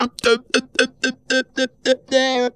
BEATVOICE4.wav